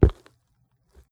footstep1.wav